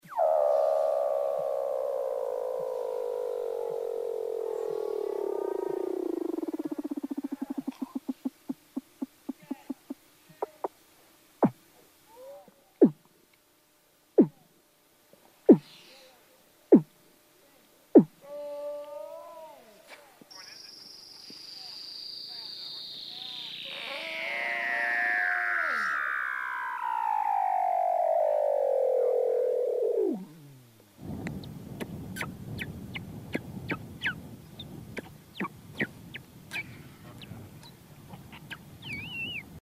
На этой странице собраны звуки морских котиков — забавные и живые голоса этих удивительных животных.
Звуки морских котиков